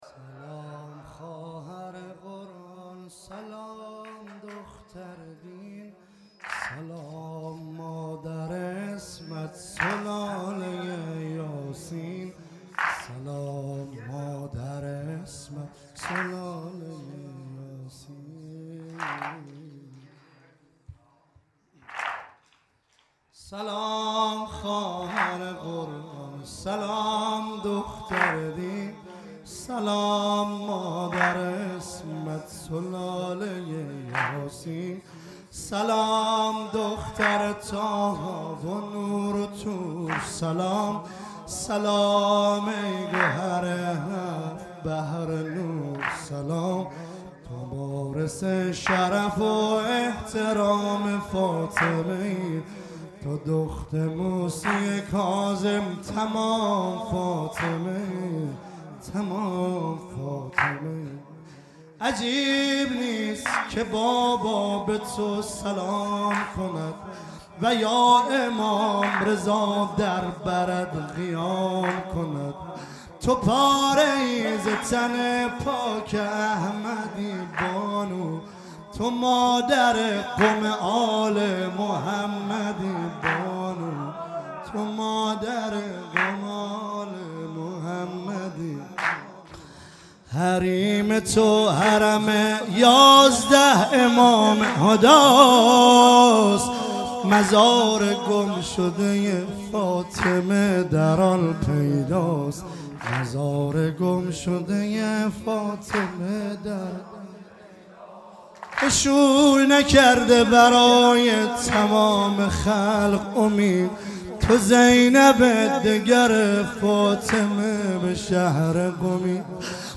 هیئت حسین جان - واحد - سلام خواهر قرآن